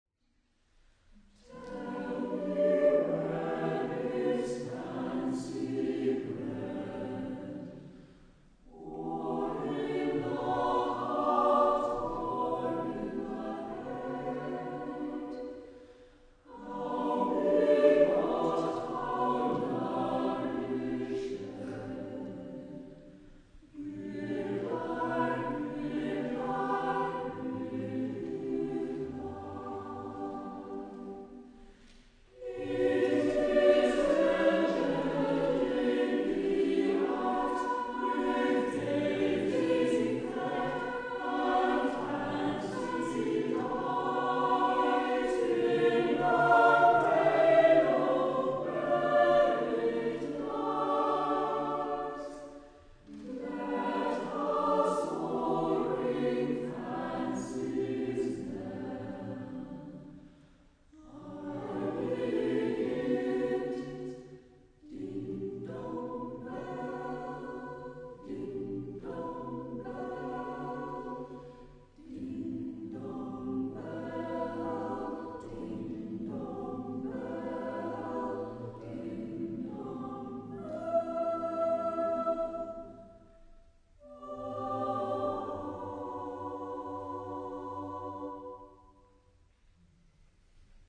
The term partsong just means a song for more than one voice, normally for a cappella choir, and applies predominantly to Romantic English and German choral songs.
Soprano Alto Tenor Bass